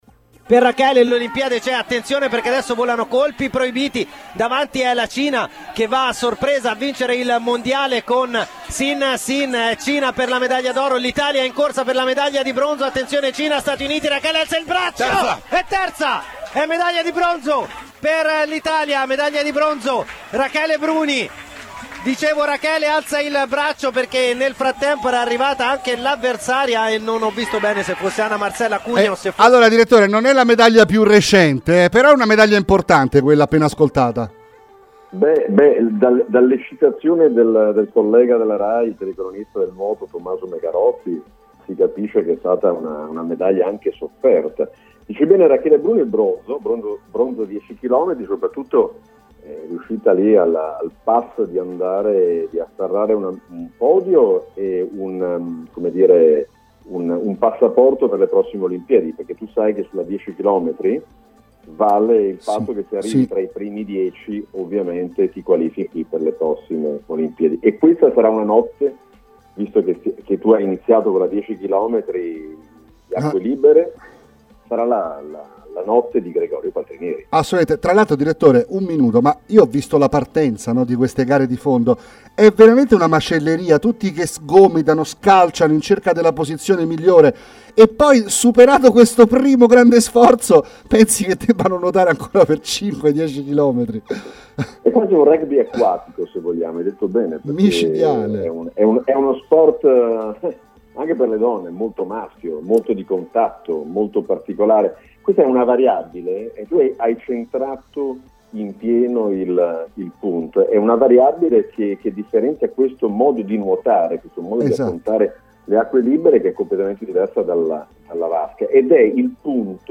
intervistato a Sport Academy su Radio Cusano Campus